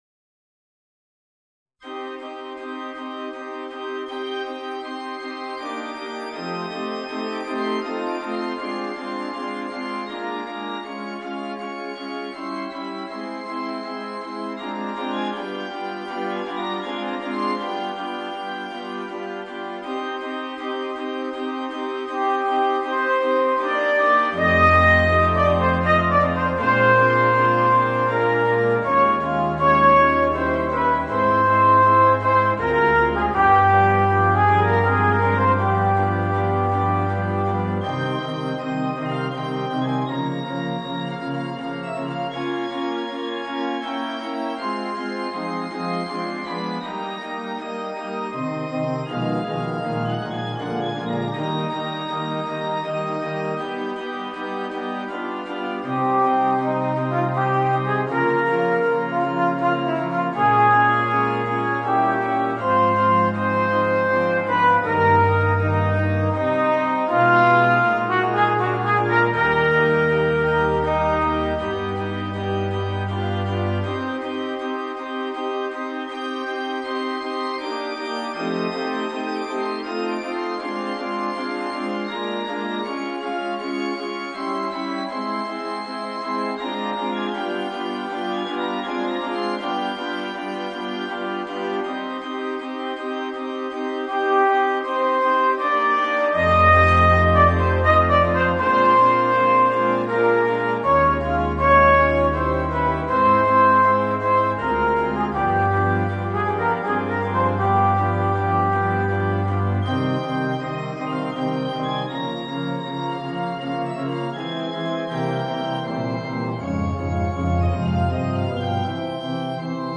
Voicing: Trumpet and Organ